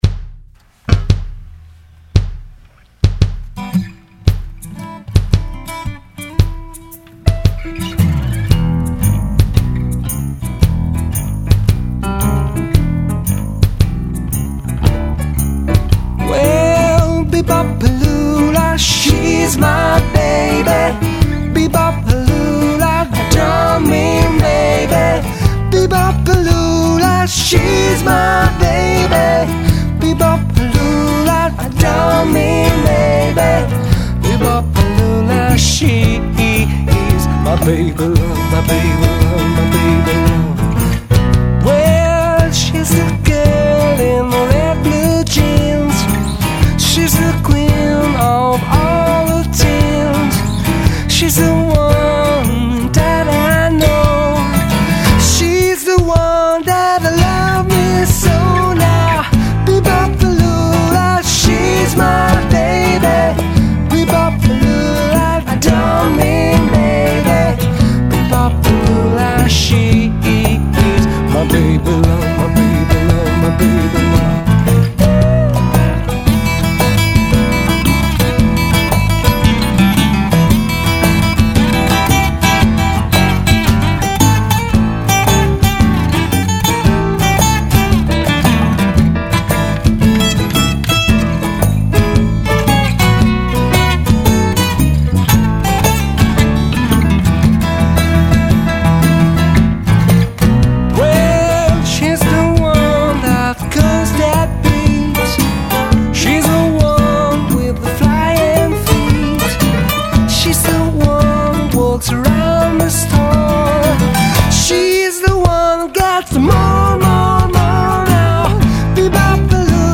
cover di vario genere riarrangiate in chiave acustica